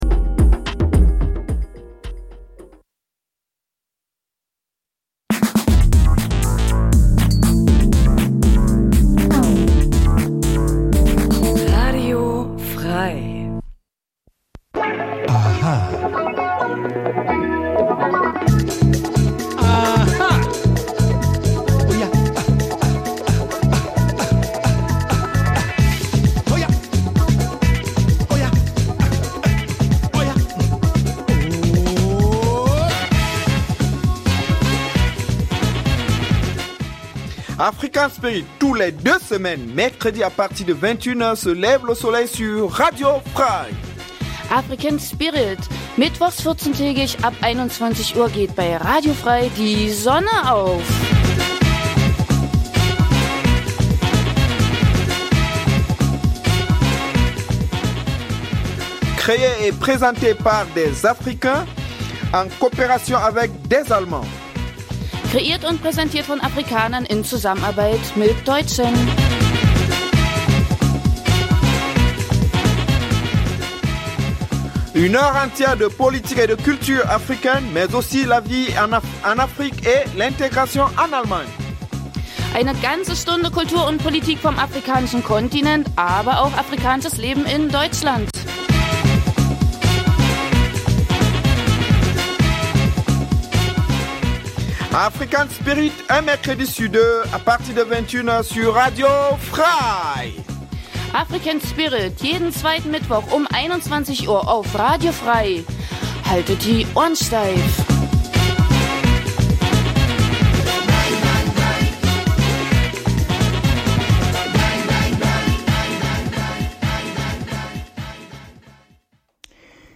Afrikanisches zweisprachiges Magazin Dein Browser kann kein HTML5-Audio.
Neben aktuellen Nachrichten gibt es regelmäßig Studiogäste, Menschen die in irgendeiner Form etwas mit Afrika zu tun haben: Zum Beispiel in Thüringen lebende Afrikaner, die uns über ihr Heimatland berichtet aber auch davon welche Beziehungen sie zu Deutschland haben oder Deutsche die aus beruflichen Gründen in Afrika waren und uns über ihre Erfahrungen berichten. Die Gespräche werden mit afrikanischer Musik begleitet.